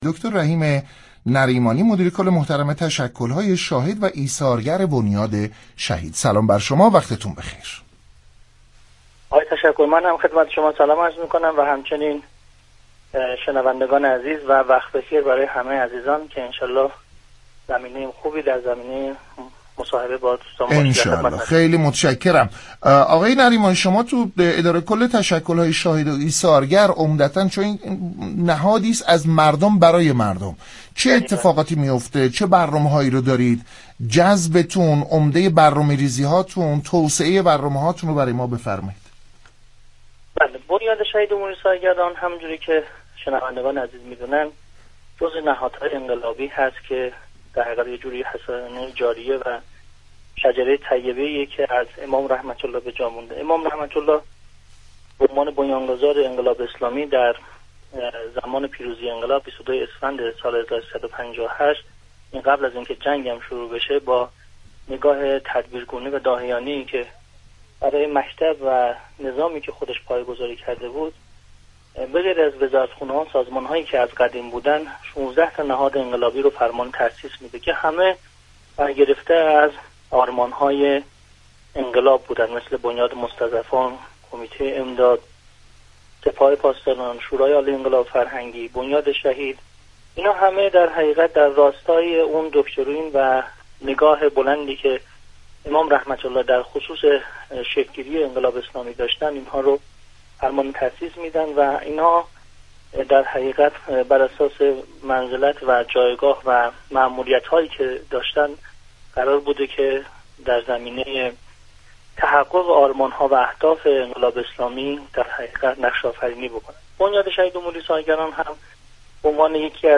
به گزارش پایگاه اطلاع رسانی رادیو تهران، رحیم نریمانی مدیركل دفتر تشكل‌های ایثارگری و مشاركت‌های مردمی در گفت و گو با رادیو تهران اظهار داشت: بنیاد شهید و امور ایثارگران جزو نهادهای انقلابی است.